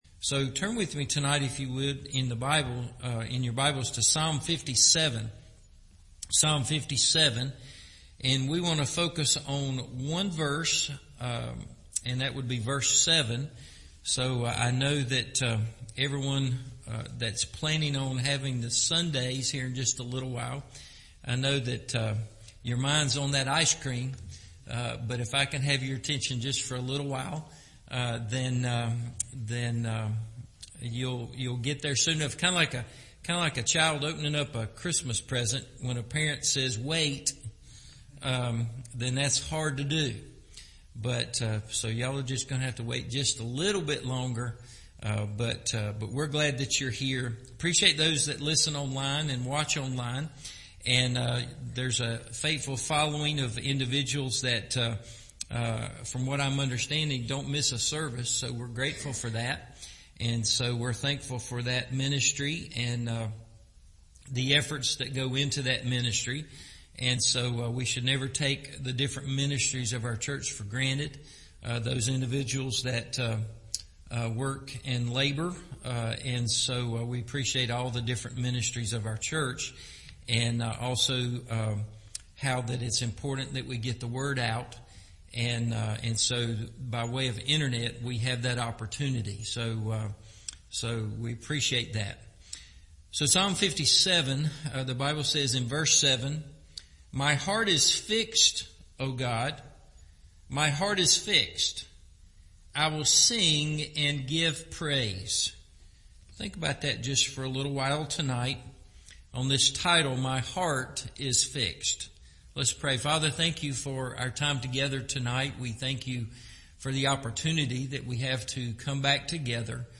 My Heart Is Fixed – Evening Service